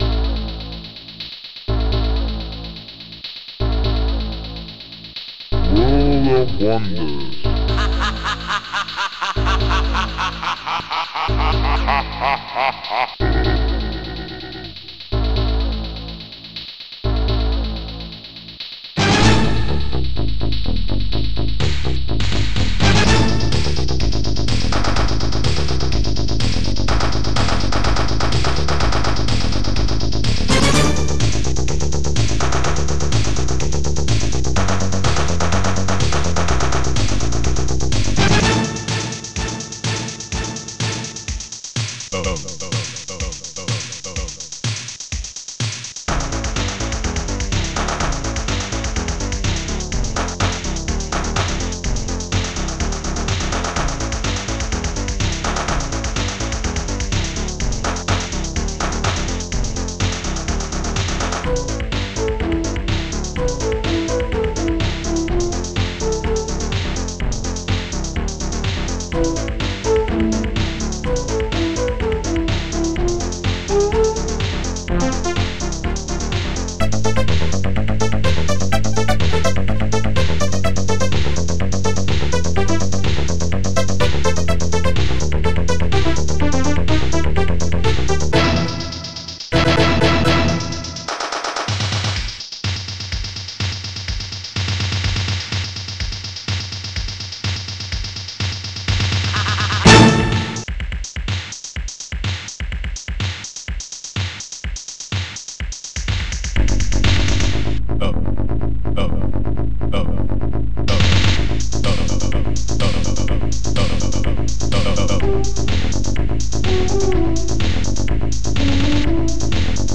orch.hit dxbass monsterbass ha bassdrum2 popsnare2 hihat2 claps1 bo tunebass shakuhachi echostring1